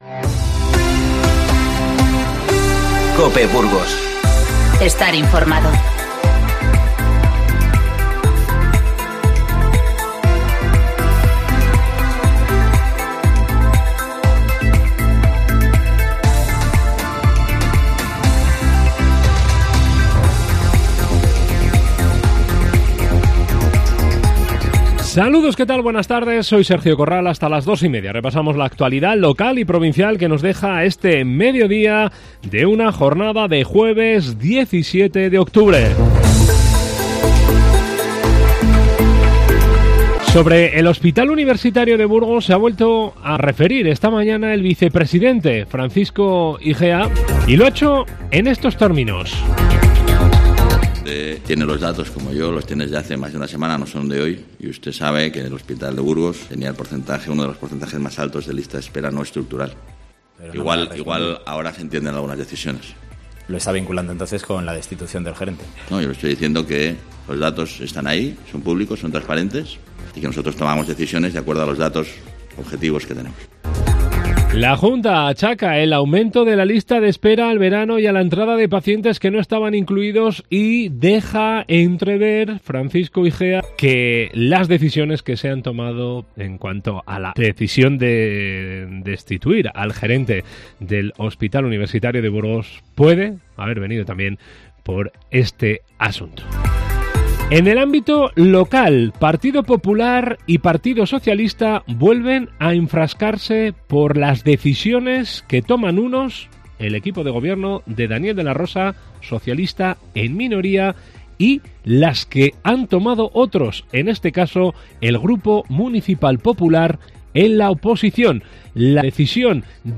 INFORMATIVO Mediodía 17-10-19